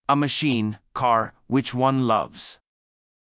※英語の声：音読さん